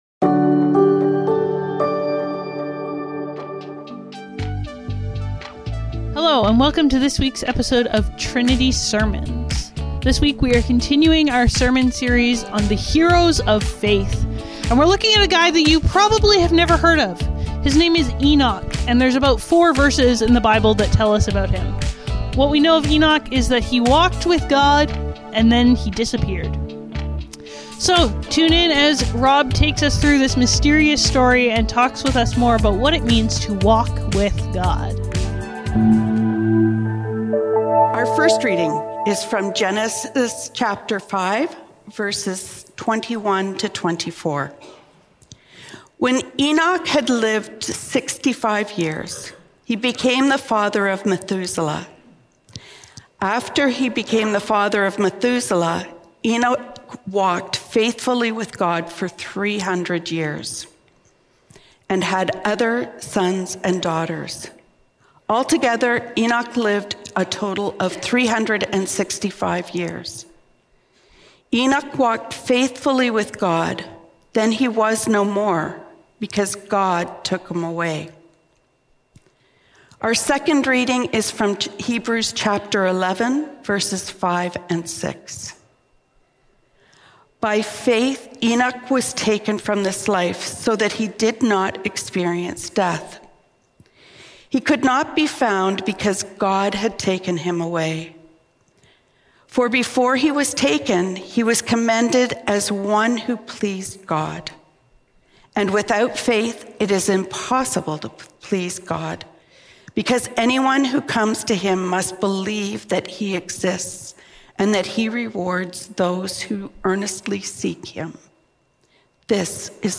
Trinity Streetsville - Enoch - Faith that Walks | Heroes of Faith | Trinity Sermons